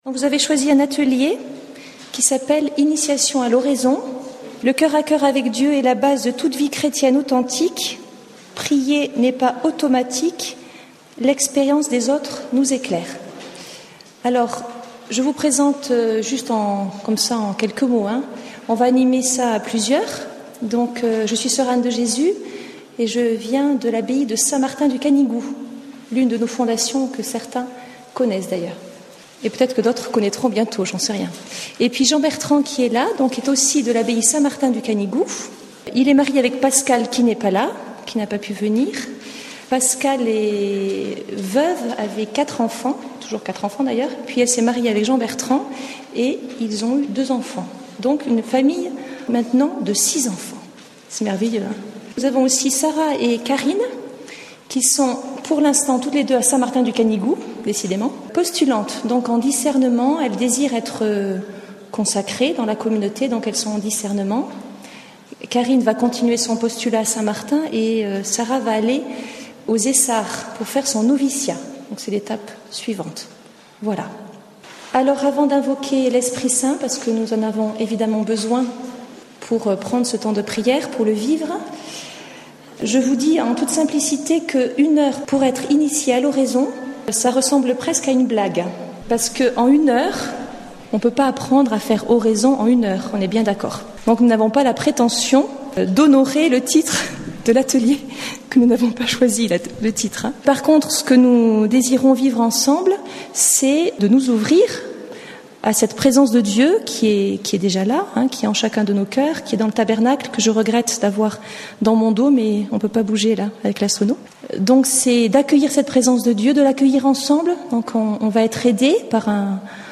Enregistr� en 2013 (Session B�atitudes Lourdes) Intervenant(s